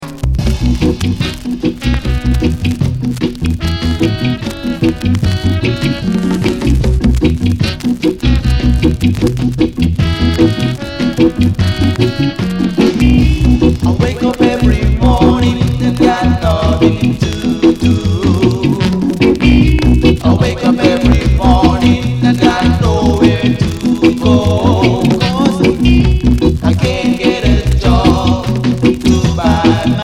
Notes: bit noisy pressing